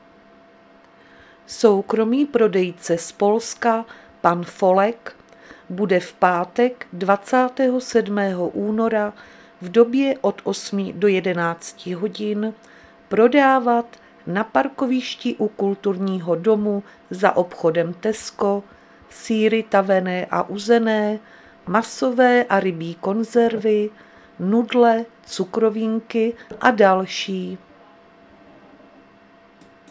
Záznam hlášení místního rozhlasu 26.2.2026